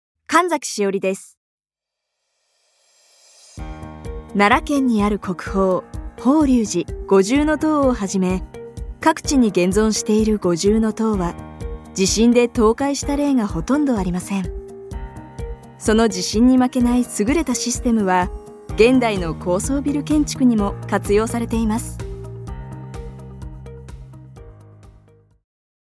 ナレーション
低めの落ち着いたトーンで、 少年からお姉さんまで 見た目とのギャップでお届けします！